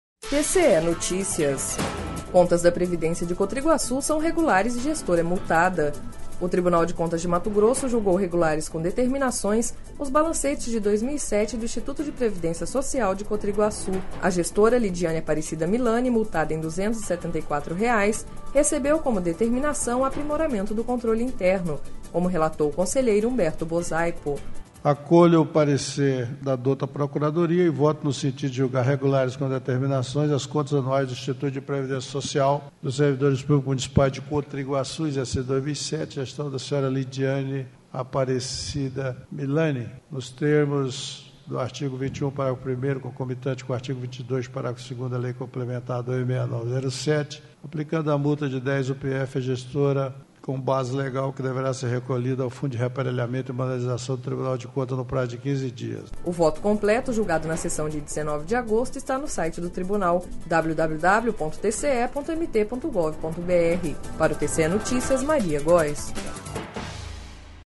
Sonora: Humberto Bosaipo – conselheiro TCE-MT